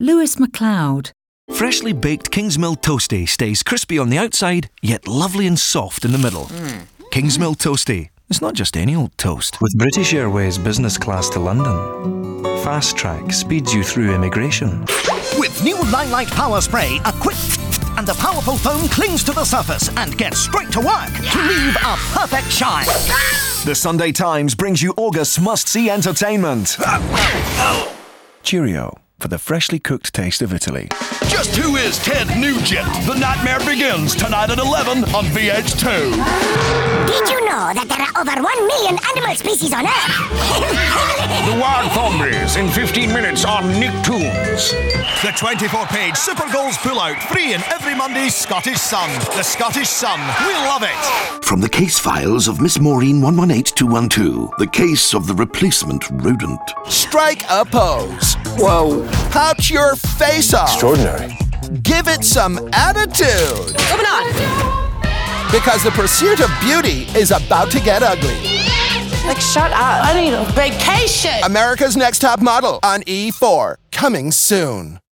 Description: Scottish: energetic, creative, a wealth of accents
Age range: 30s - 60s
Commercial 0:00 / 0:00
American, Geordie, Glasgow*, Irish (Northern), Irish (Southern), London, RP, Scottish*, Scouse